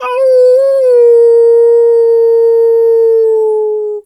wolf_2_howl_long_03.wav